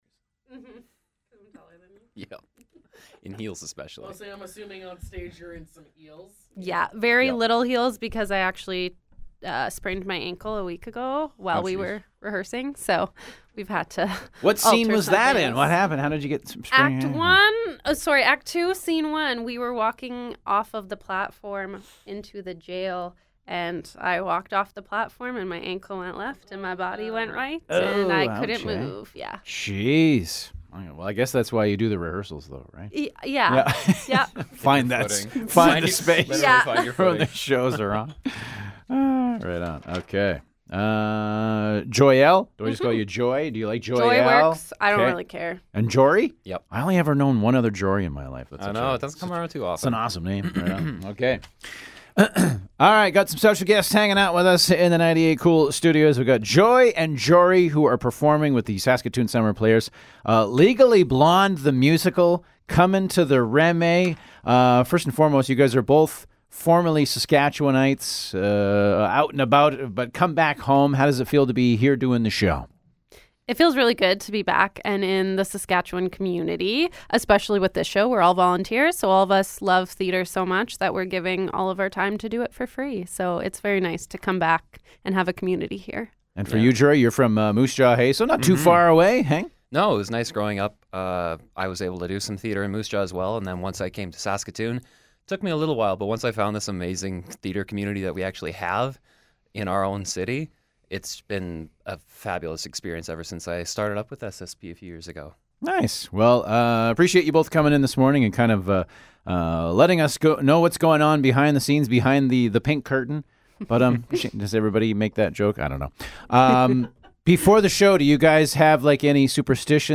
Interview: Saskatoon Summer Players presents Legally Blonde The Musical
legally-blonde-interview.mp3